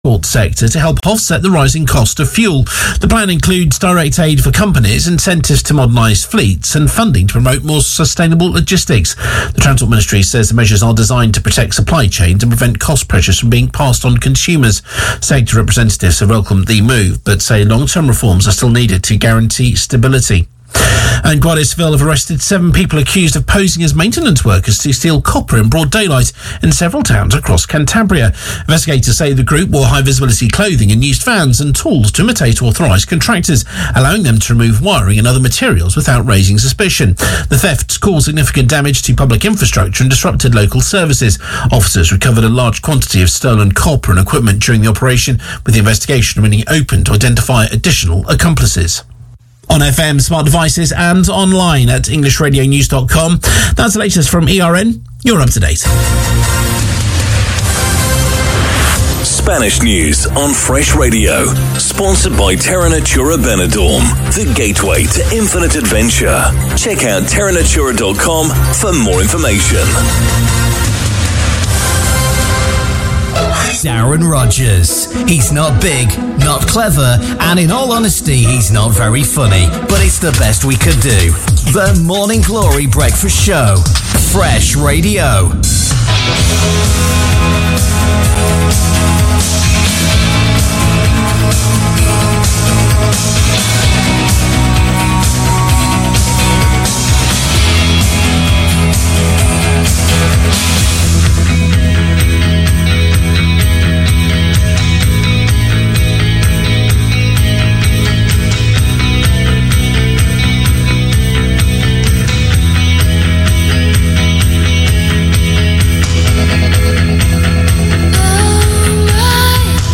I spin a song intro backwards.
Two songs smashed together.